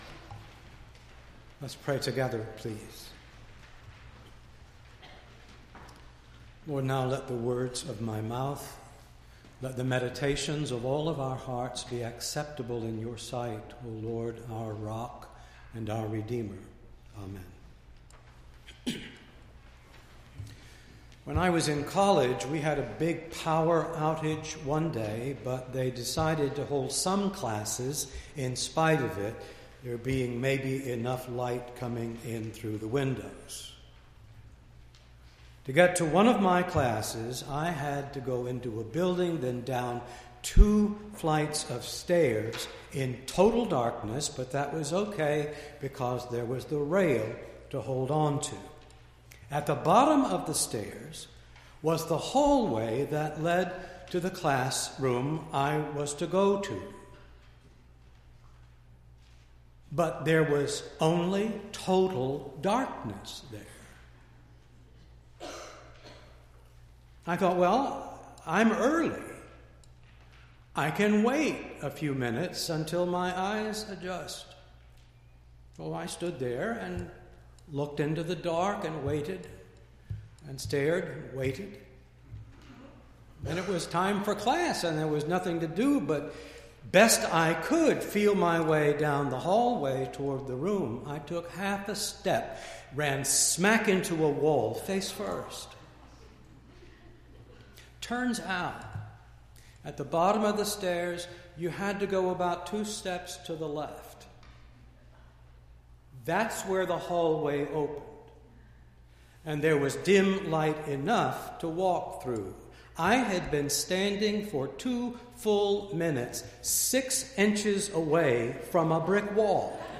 2-5-17-sermon.mp3